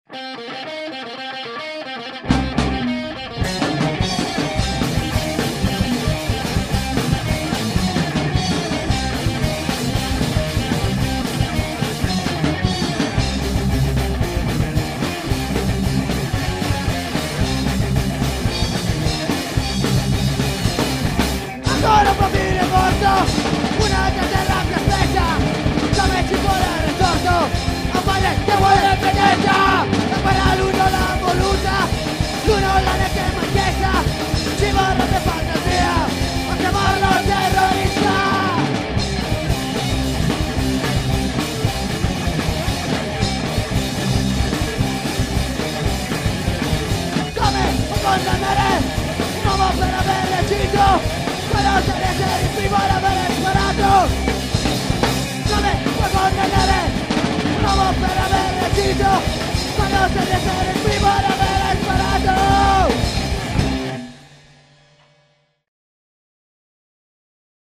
Genere: Punk Hardcore
chitarra e voce
chitarra e cori